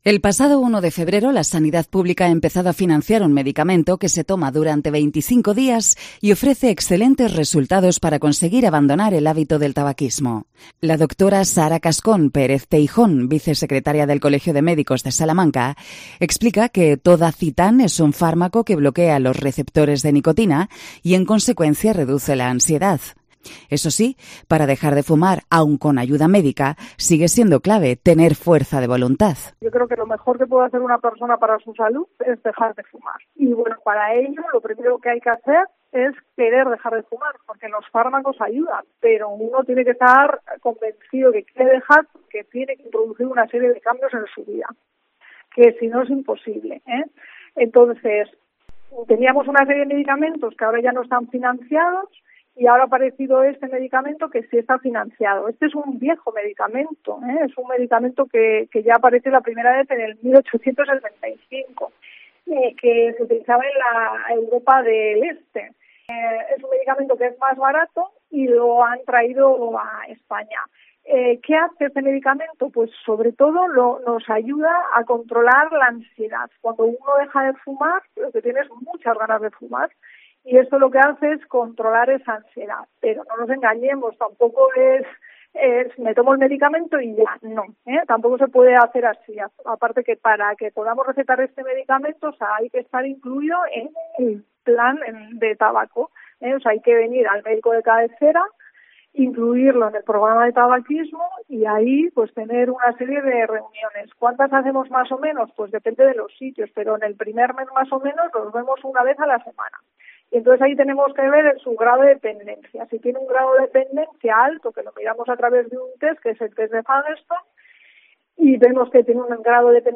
Los micrófonos de COPE han recogido hoy los testimonios de algunos salmantinos que en todo caso ven con buenos ojos la idea de optar por dejar de fumar y apostar por una vida sana.